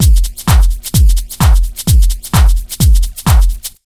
129BEAT1 3-R.wav